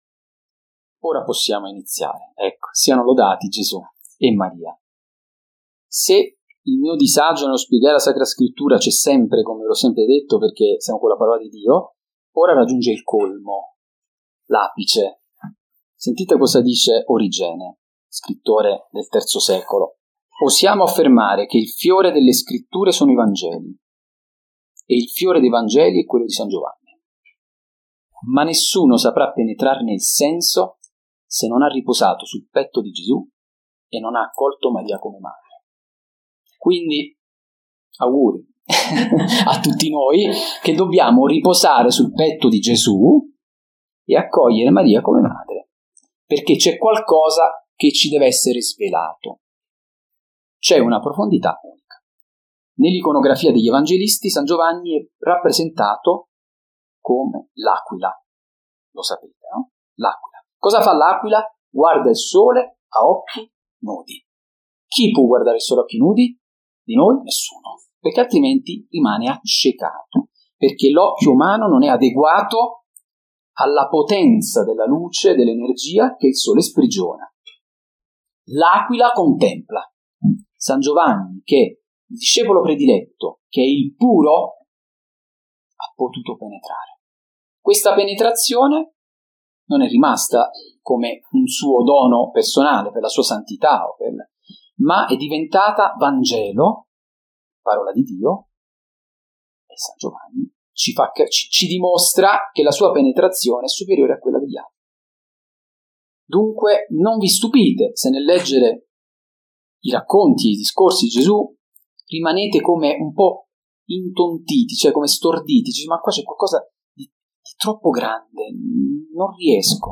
Cari fratelli e sorelle, ho esposto una conferenza sul Vangelo di San Giovanni ad un gruppo di preghiera.
conferenza-biblica_il-vangelo-di-san-giovanni-2.mp3